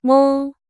After playing around with it for a bit, the best I could do was to use a slightly different model. Here’s what it will sound like after it deploys in the next half-hour: